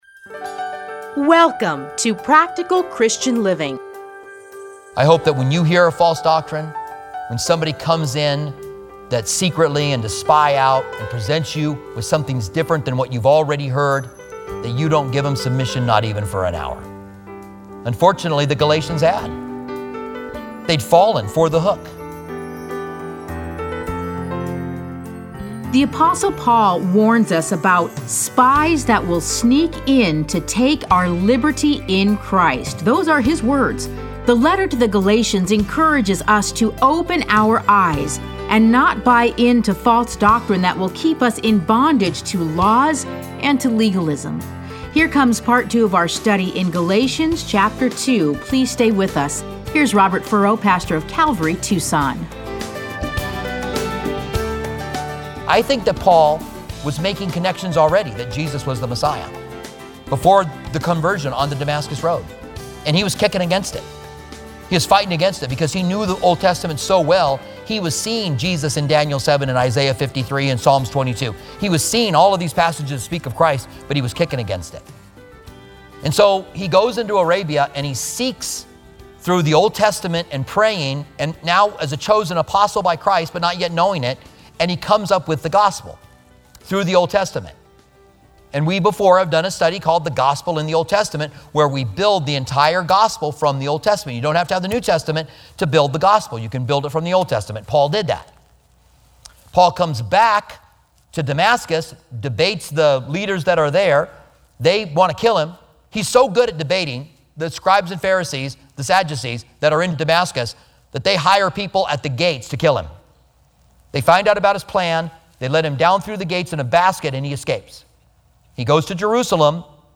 Listen to a teaching from Galatians 2:1-10.